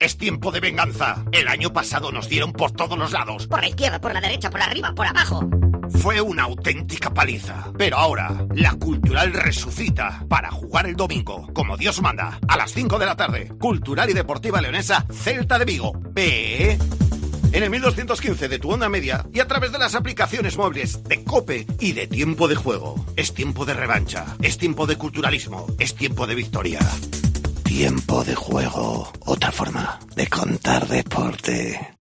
Escucha la cuña promocional del partido Cultural-Celta de Vigo el día 05-12-21 a las 17:00 h en el 1.215 OM